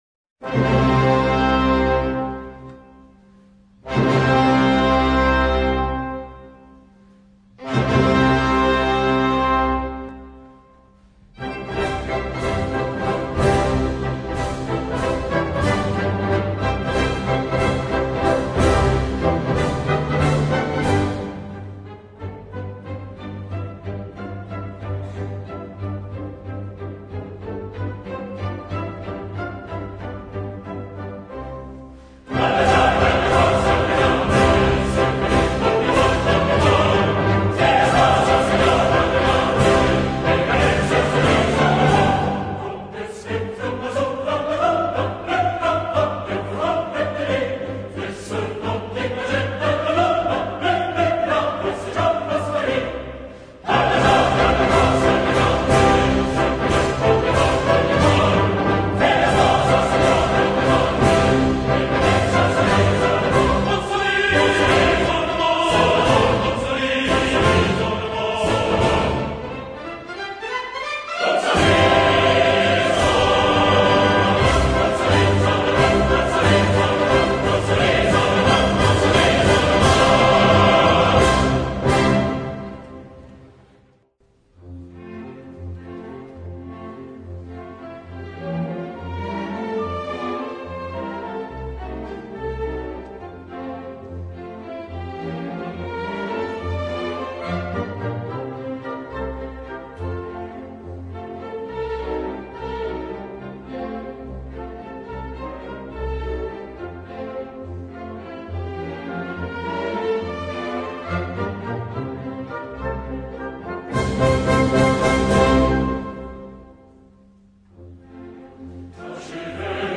per coro (ad lib) e banda